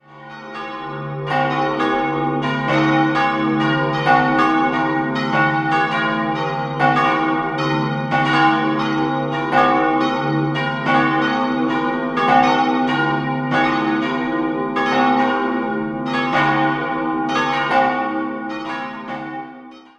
An den gotischen Turm der Pfarrkirche wurde 1717 bis 1719 ein barocker Neubau angefügt und prächtig ausgestattet. 4-stimmiges Salve-Regina-Geläute: des'-f'-as'-b' Die zweitgrößte Glocke wurde 1737 von Josef Weber in Augsburg gegossen. Alle anderen Glocken sind aus Eisenhartguss und entstanden 1926 bei Schilling&Lattermann.